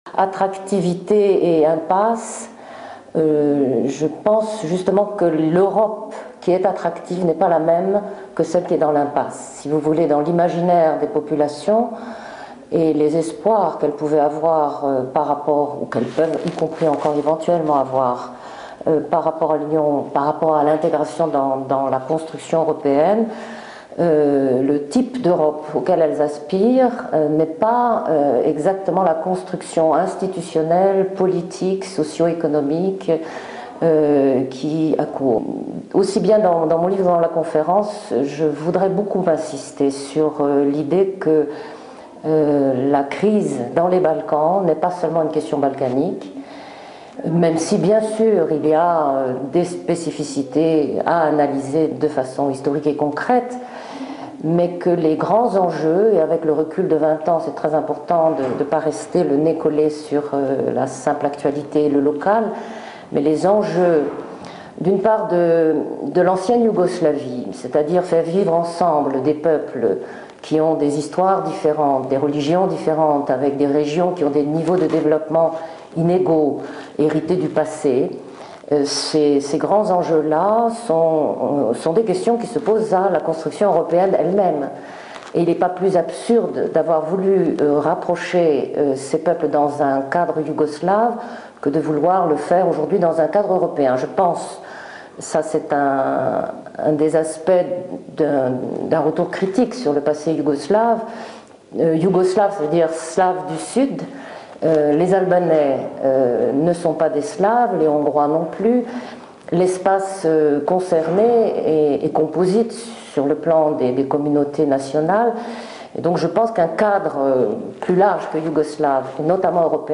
La vidéo originale de l’IRIS (Institut de Relations Internationales et Stratégiques) a été convertie en deux fichiers MP3 distincts car cette dernière était d’une piètre qualité (image dégradée accompagnée d’un décalage permanent entre l’élocution et la bande sonore).